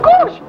Ooff3.wav